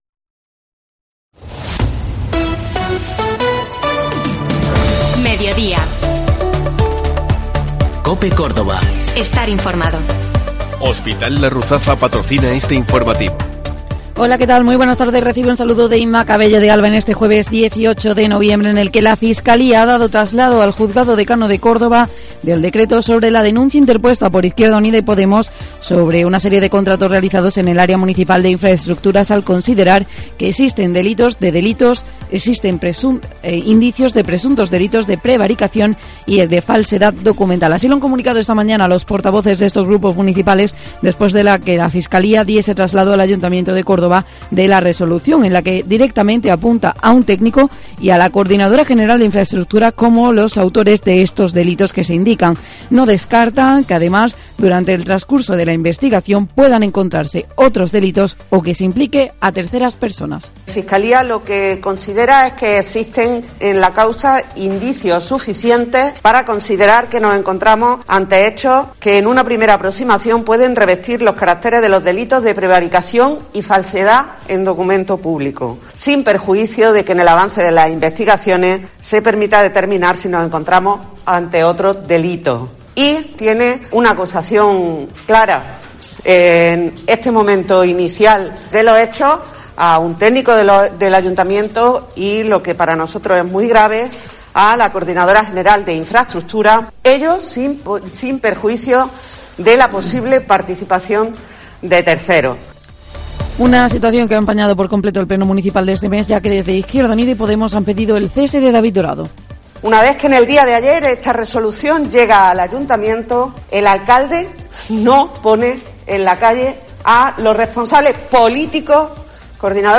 Informativo Mediodía COPE Córdoba